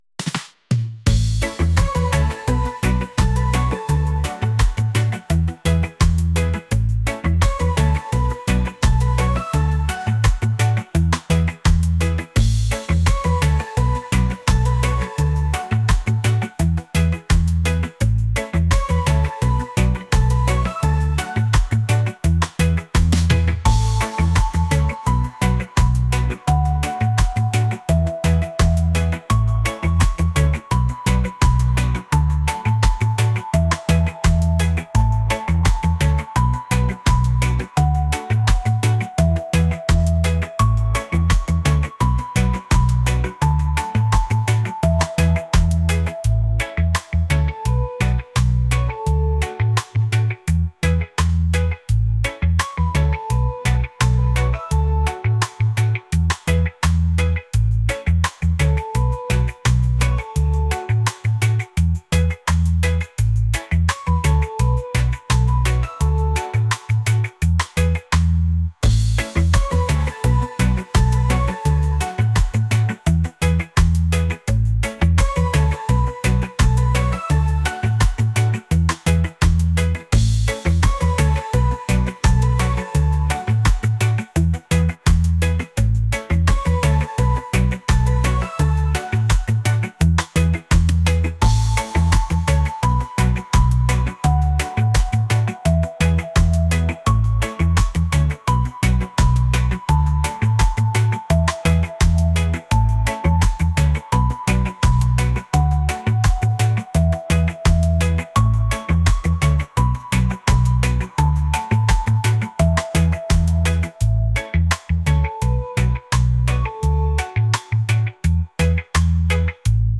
reggae | pop | latin